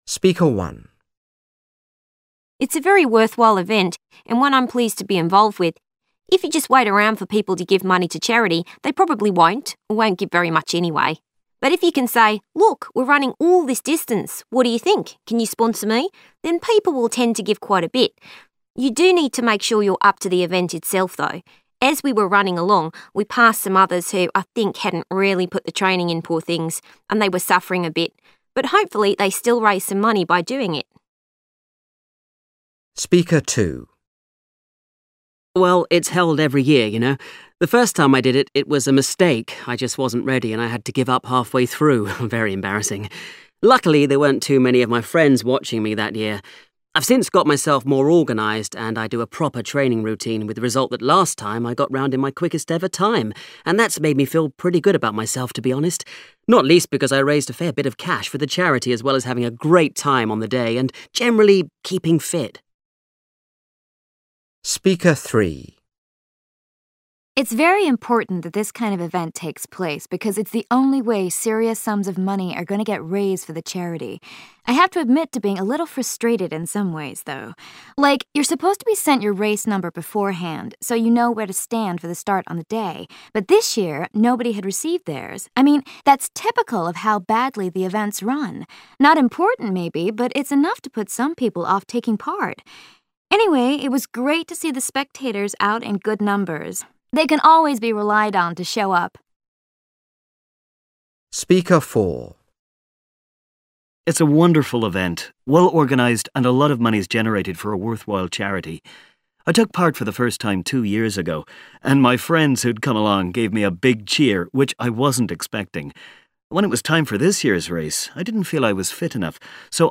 Part 3: You will hear five different people talking about a charity running event.